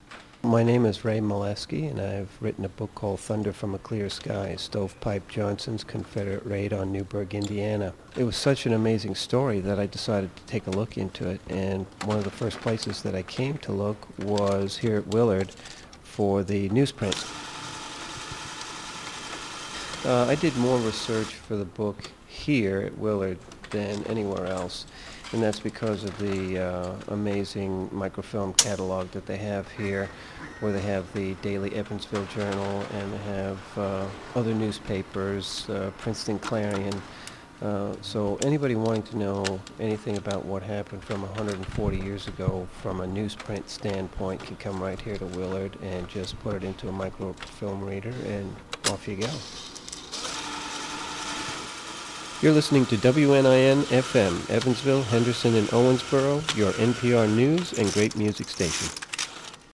Radio clip
The first clip was done standing outside the Rutledge House in Newburgh (called the Newburgh Clip) and the second clip was done at the upstairs genealogy research section of Willard Library (called the Willard Clip). The voice you hear in both clips is the nasal New York of yours truly.